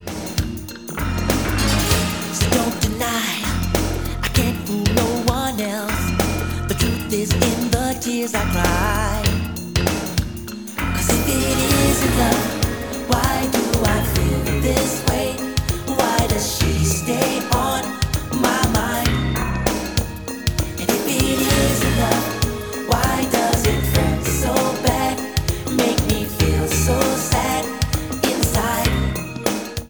• R&B/Soul
American R&B quintet